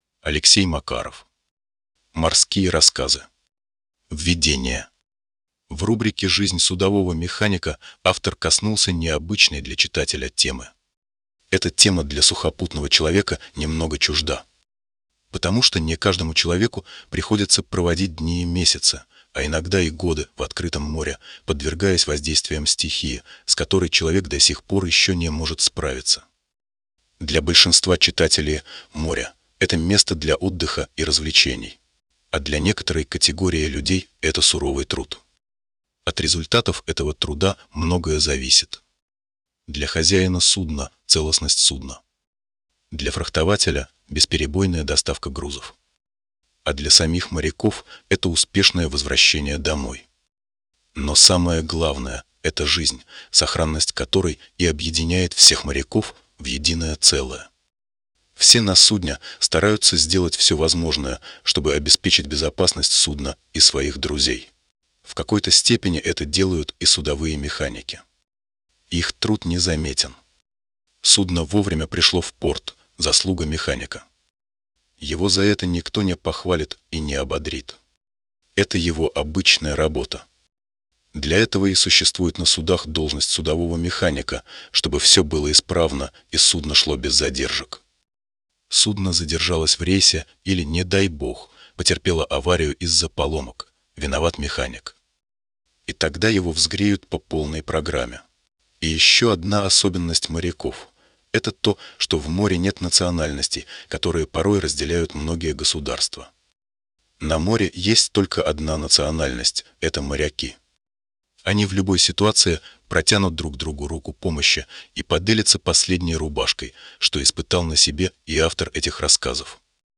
Аудиокнига Морские рассказы. Избранное | Библиотека аудиокниг